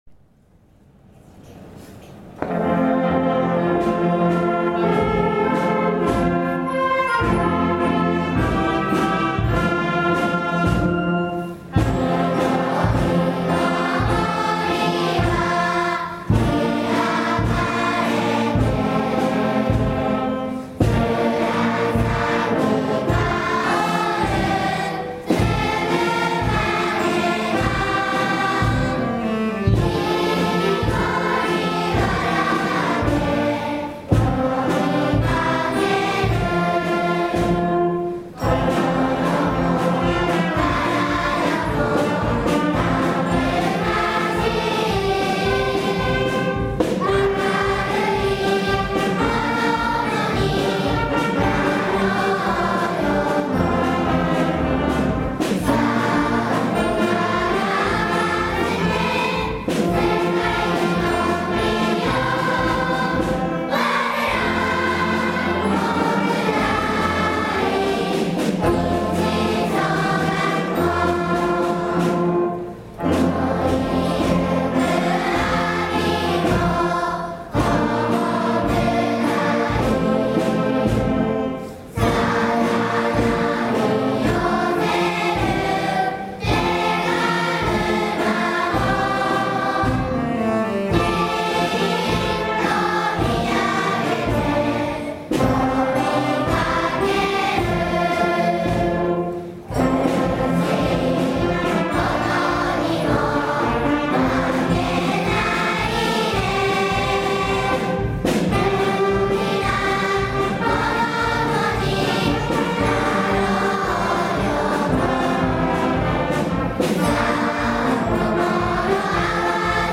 二学期終業式
校歌斉唱_.mp3